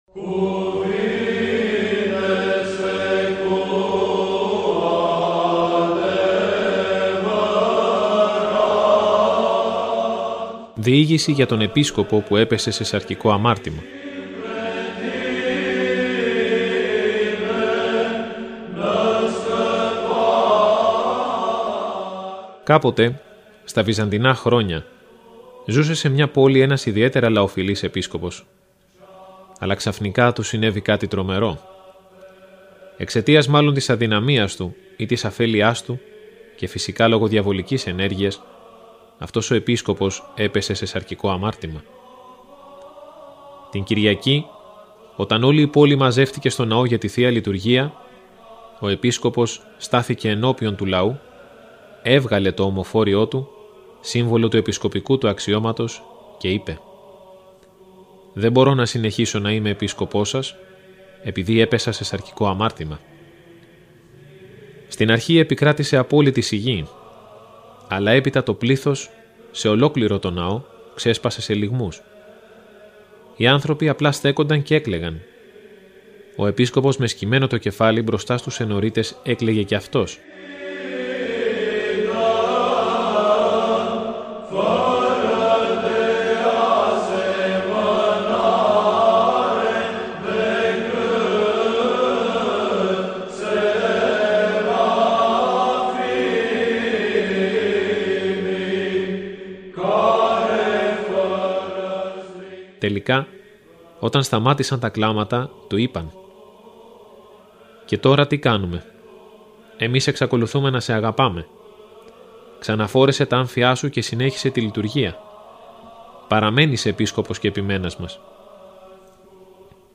Ακούστε το επόμενο κείμενο, όπως αυτό “δημοσιεύθηκε” στο 151-ο τεύχος (Ιανουαρίου – Φεβρουαρίου του 2015) του ηχητικού περιοδικού μας, Ορθόδοξη Πορεία.